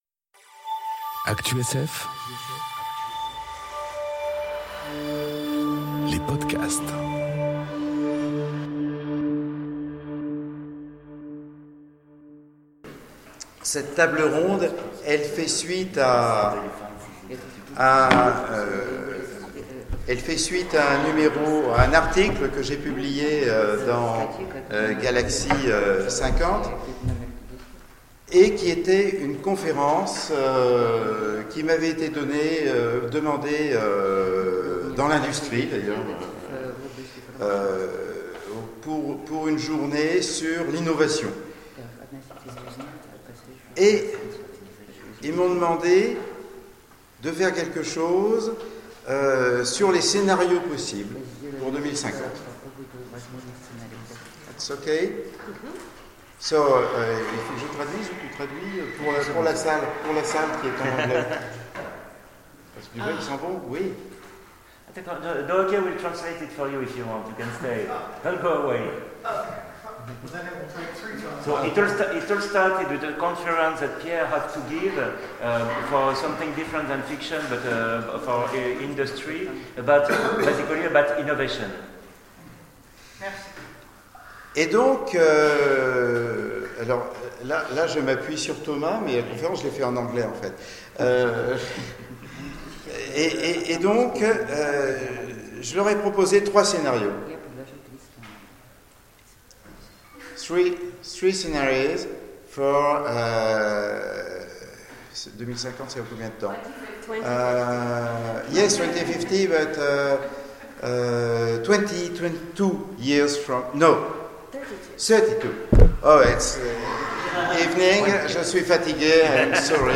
Eurocon 2018 : Conférénce Le Monde en 2050